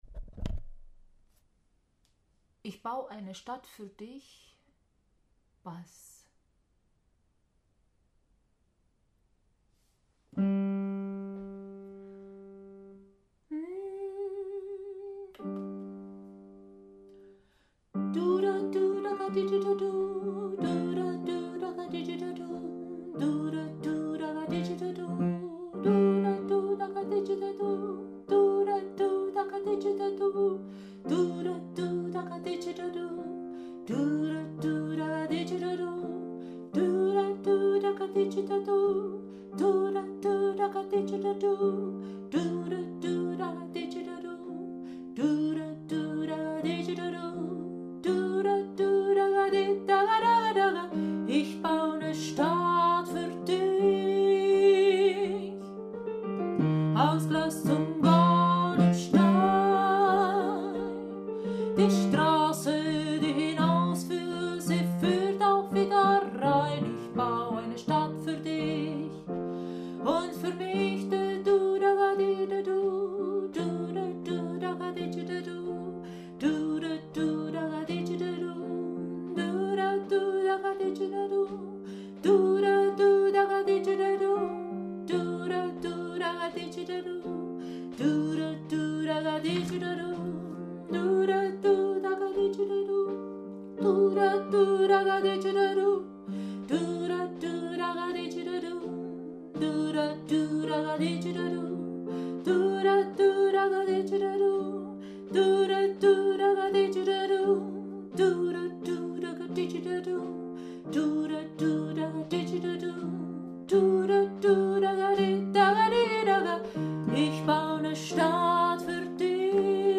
Stadt-für-Dich-Bass.mp3